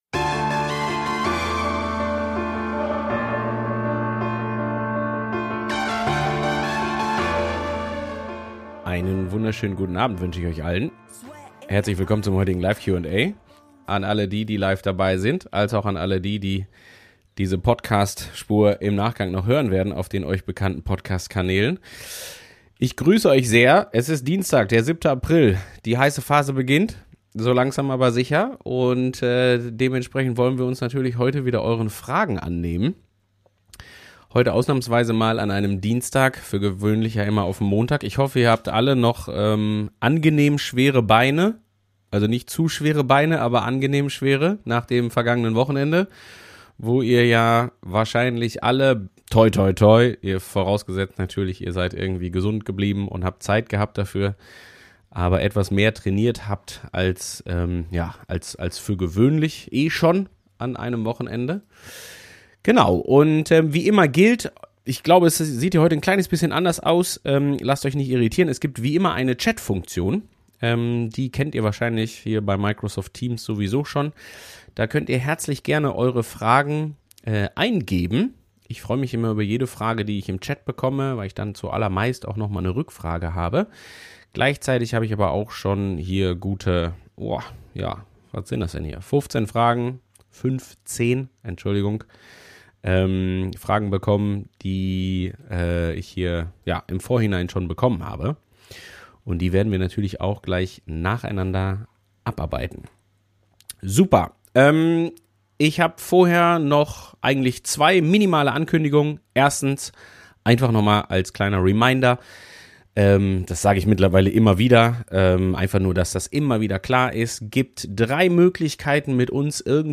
In dieser Live-Q&A-Session